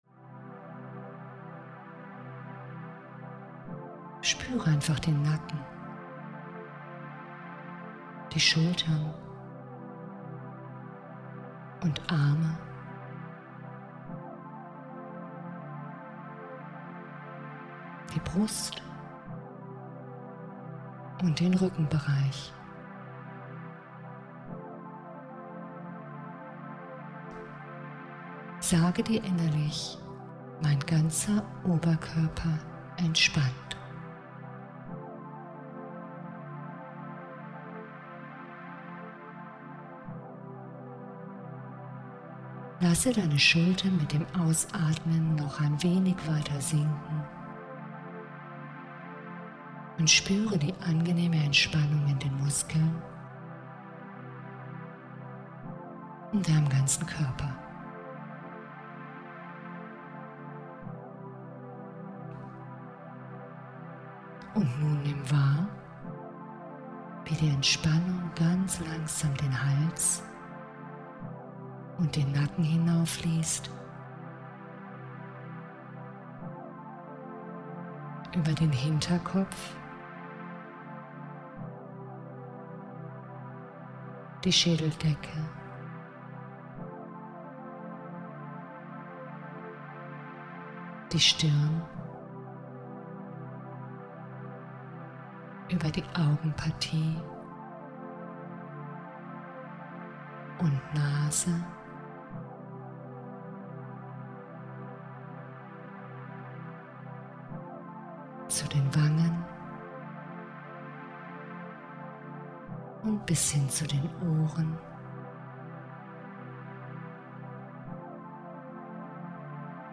Geführte Meditation
Erste-Hilfe-Meditation.mp3